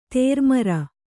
♪ tērmara